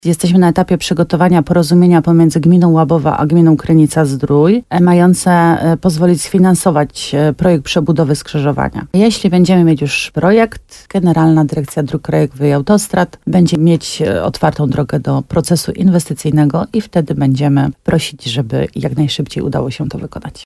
– To bardzo niebezpieczne miejsce – powiedziała wójt gminy Łabowa Marta Słaby w programie Słowo za Słowo w radiu RDN Nowy Sącz.